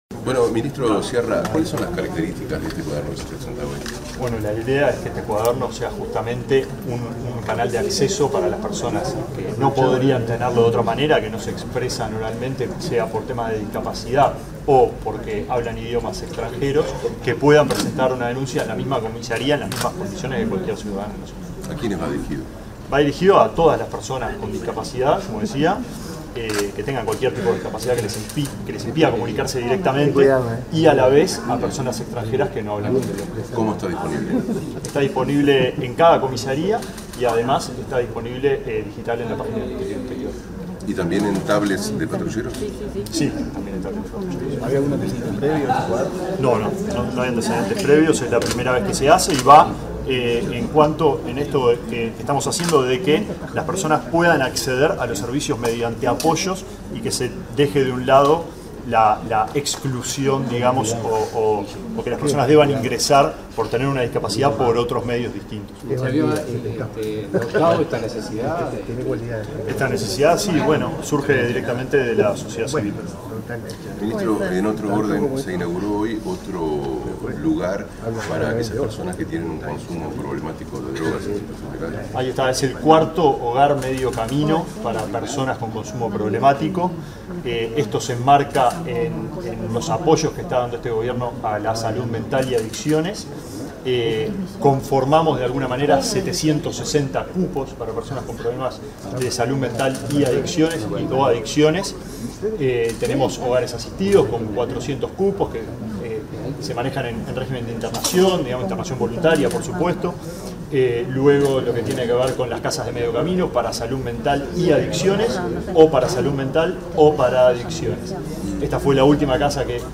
Declaraciones del ministro de Desarrollo Social, Alejandro Sciarra